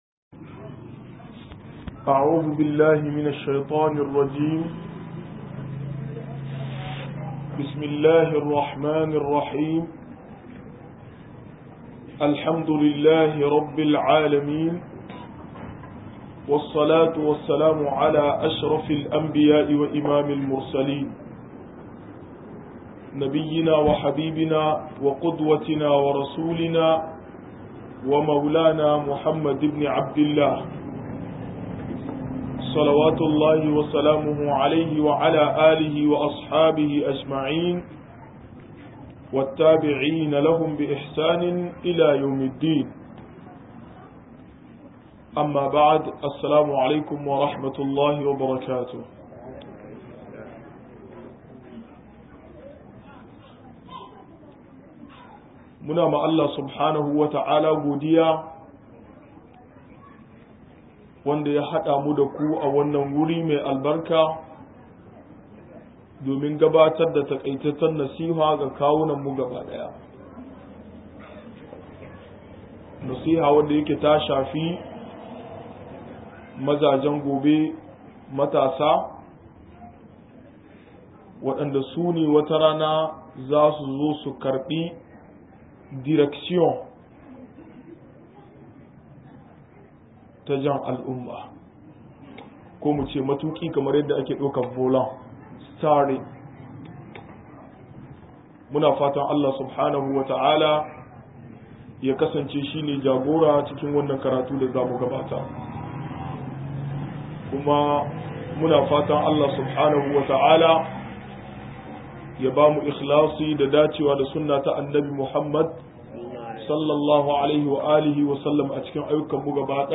51-tatalin arziki - MUHADARA